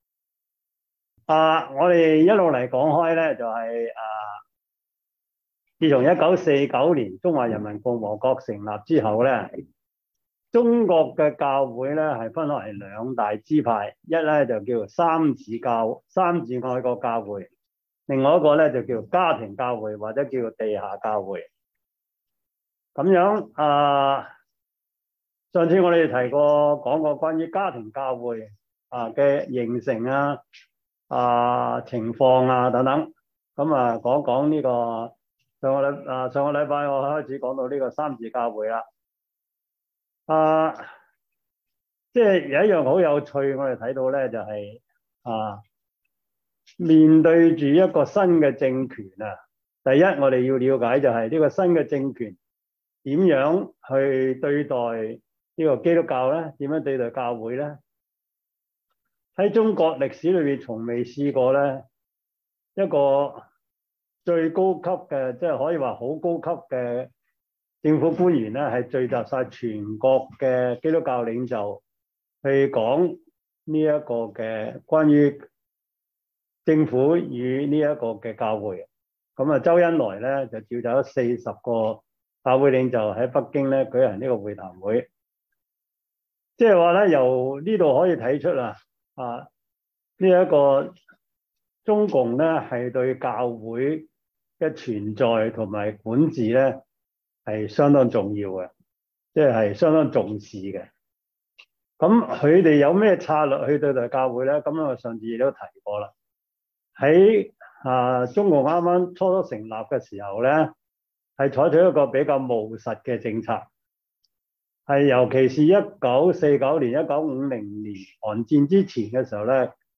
教會歷史 Service Type: 中文主日學 中國教會史